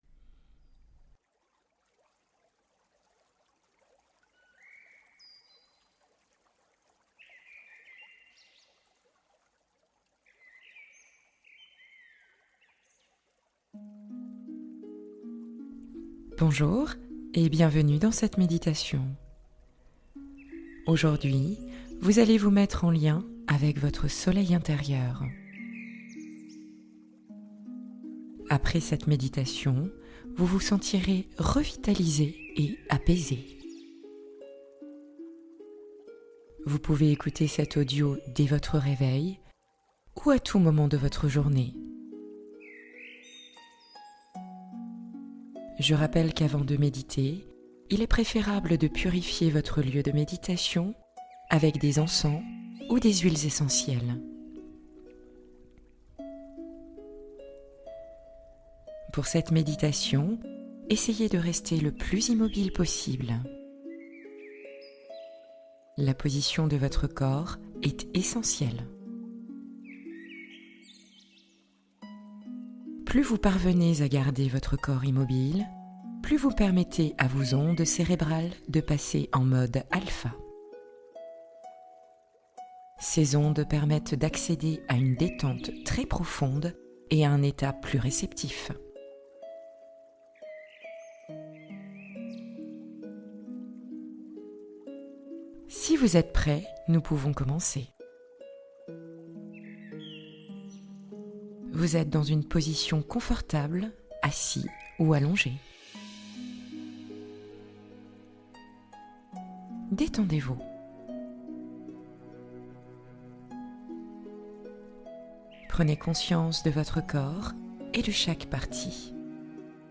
Réveillez le soleil qui brille en vous | Méditation matinale pour illuminer votre journée